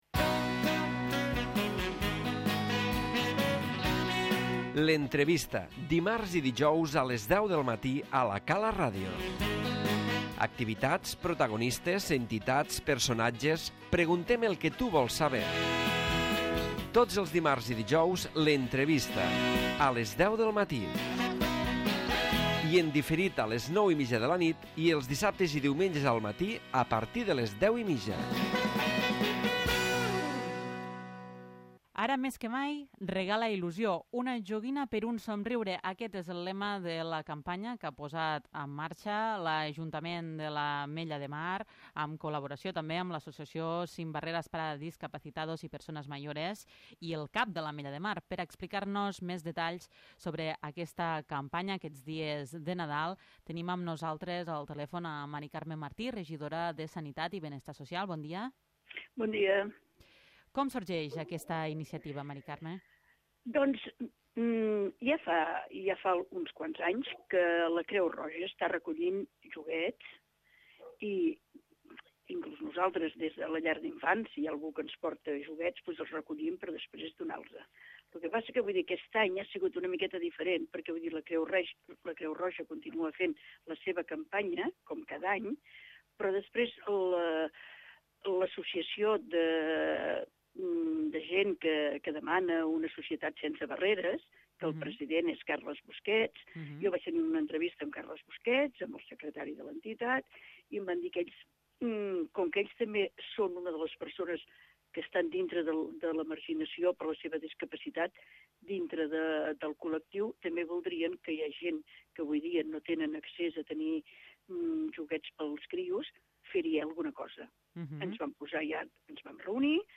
L'Entrevista
Mari Carme Martí, regidora de Benestar Social ens parla sobre la campanya de recollida de joguines, una joguina x un somriure.